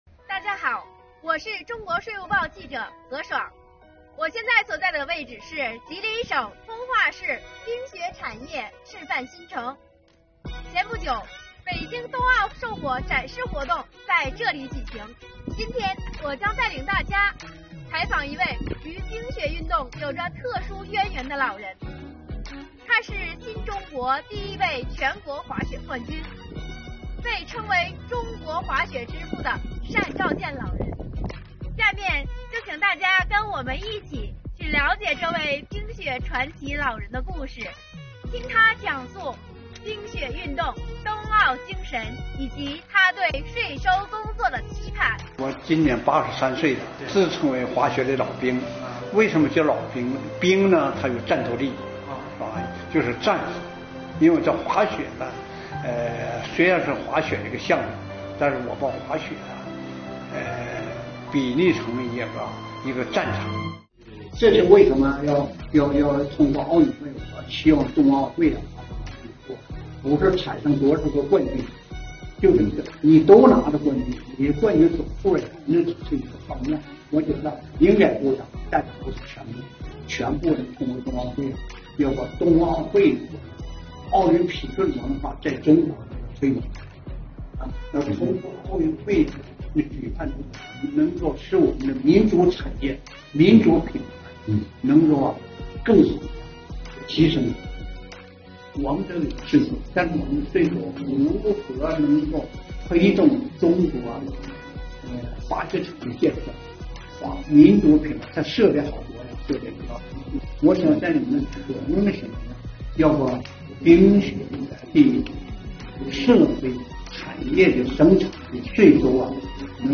标题: “中国滑雪之父”单兆鉴接受本报专访并寄语税务部门
春节前夕，记者在通化市冰雪产业示范新城采访了这位中国滑雪界传奇人物，听他讲述中国冰雪运动的历史、即将开赛的北京冬奥会，以及冰雪产业的未来。